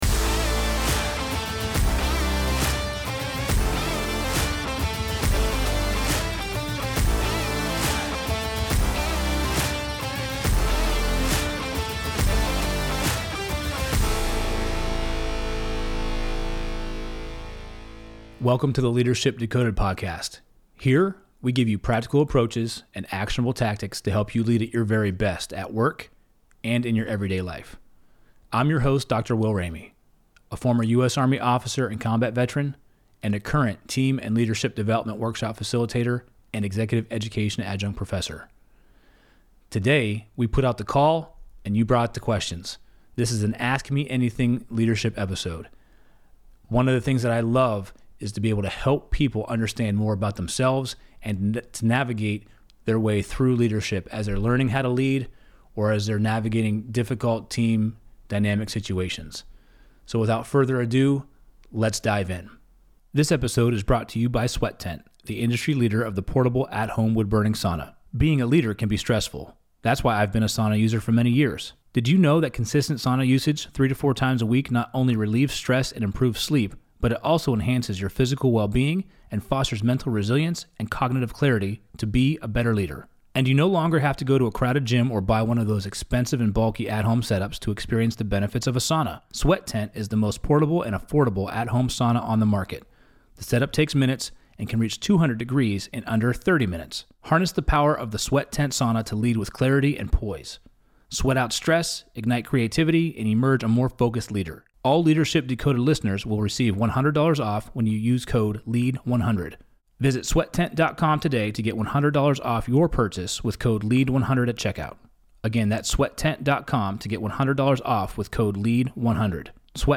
Ask Me Anything Leadership Q&A | Ep.034